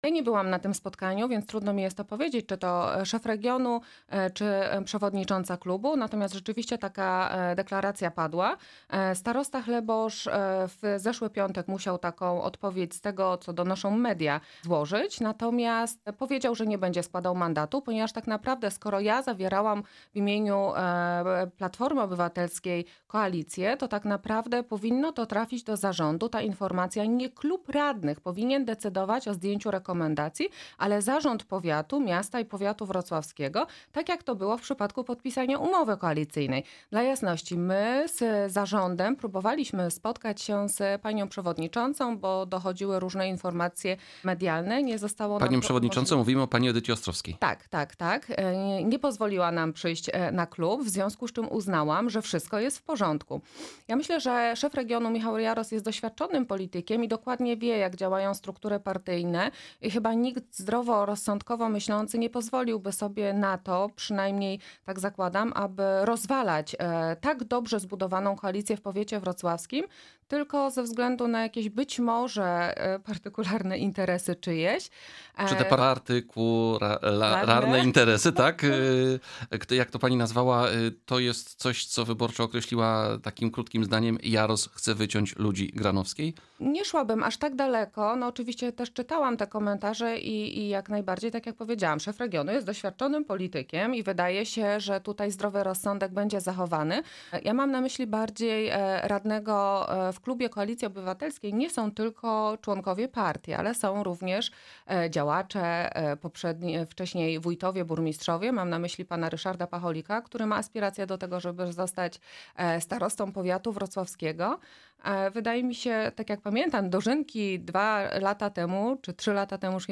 Naszym „Porannym Gościem” była posłanka na Sejm Koalicji Obywatelskiej Anna Sobolak.